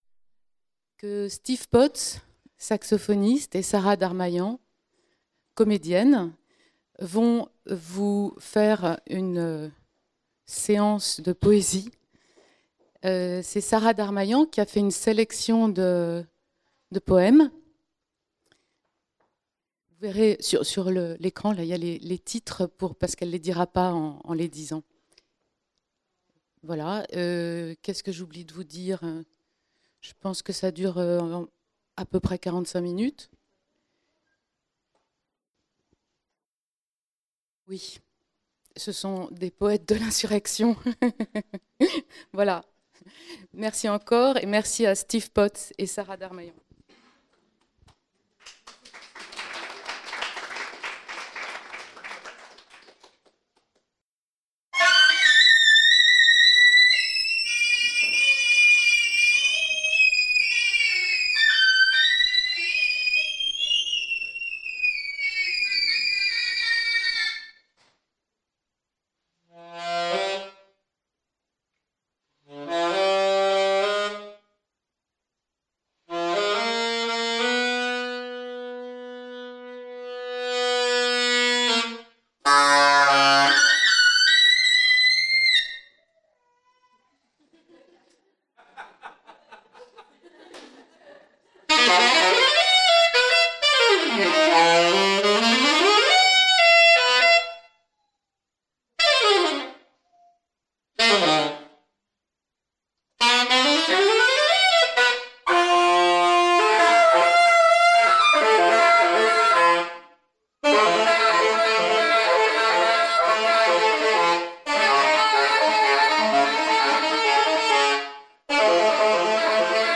Lecture
poésie et free jazz : concert enregistré au Salon de lecture Jacques Kerchache le dimanche 22 mars 2015